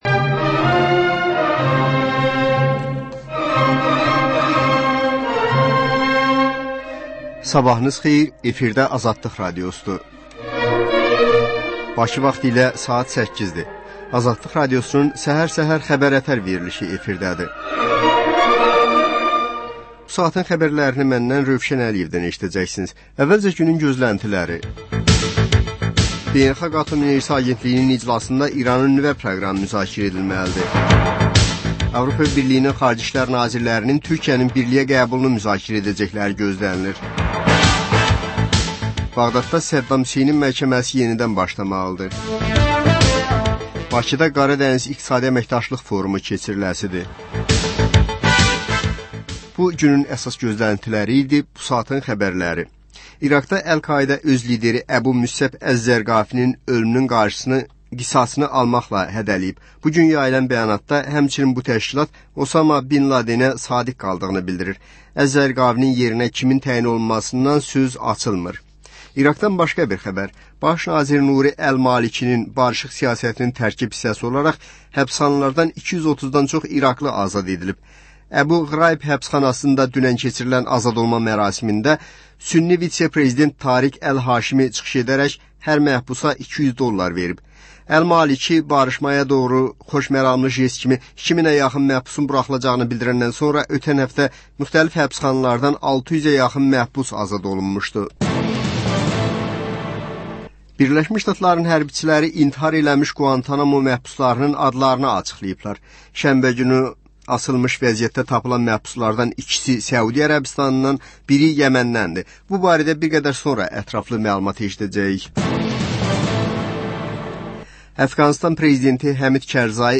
Səhər-səhər, Xəbər-ətər: xəbərlər, reportajlar, müsahibələr İZ: Mədəniyyət proqramı. Və: Tanınmışlar: Ölkənin tanınmış simalarıyla söhbət.